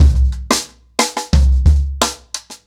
Expositioning-90BPM.3.wav